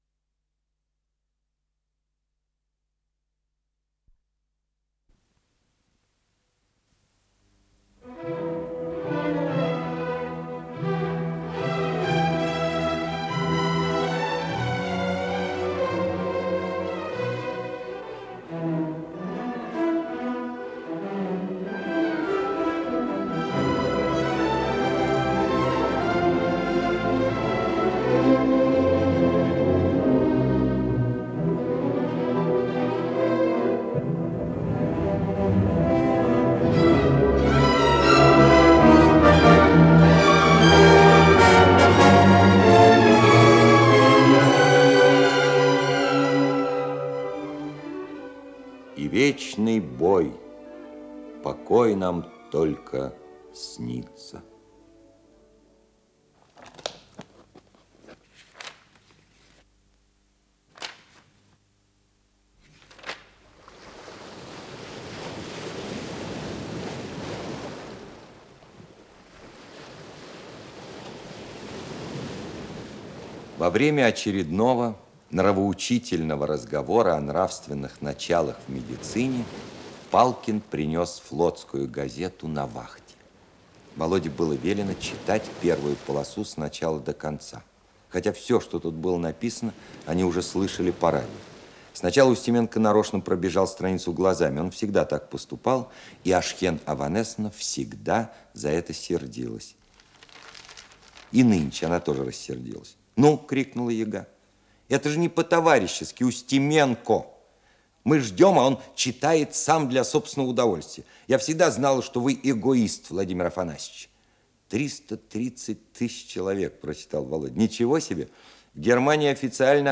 Исполнитель: Алексей Баталов - чтение
Композиция по роману